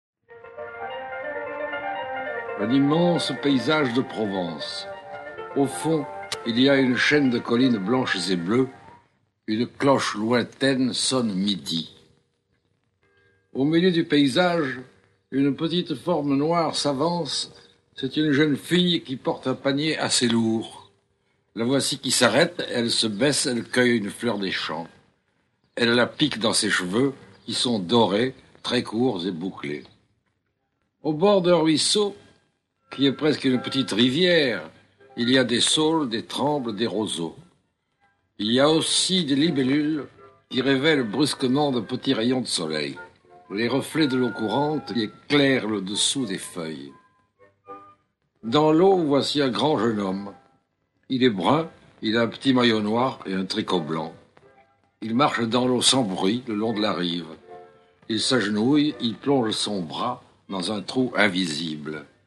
avec Fernandel (Felipe) Raimu (Pascal le puisatier) Josette Day (Patricia) Charpin (M. Mazel) et 6 autres comédiens Enregistrement original